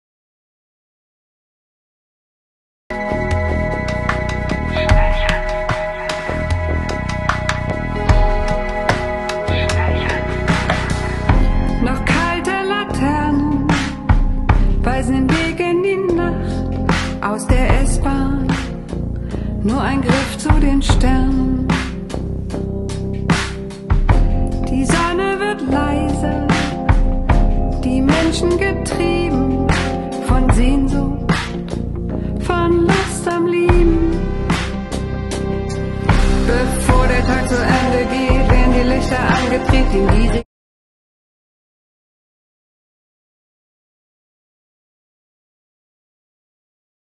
Teil 2: Musik